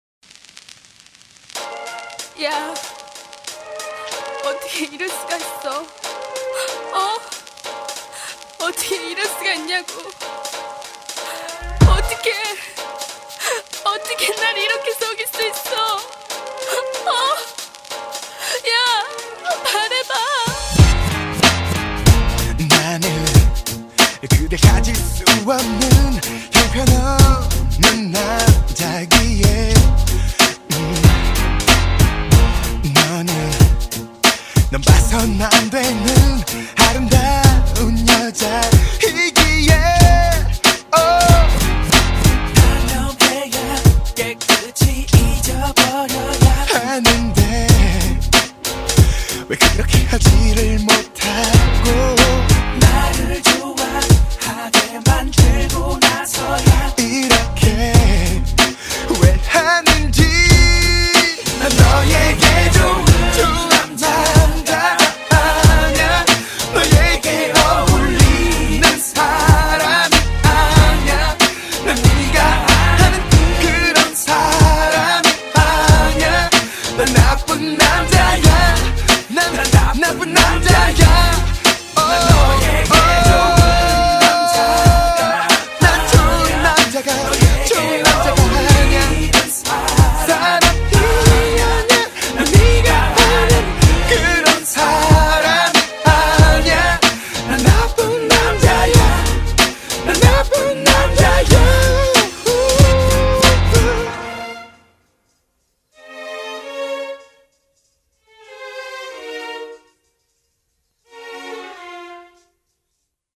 BPM70--1
Audio QualityPerfect (High Quality)